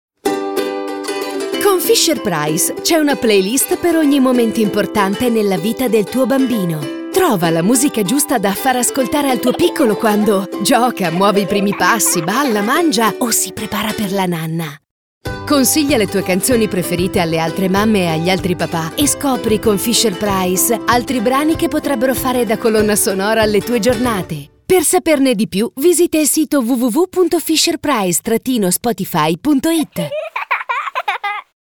Fisher Price Spot Radio